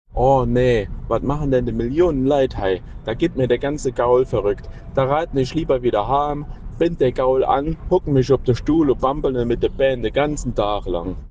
auf Trierisch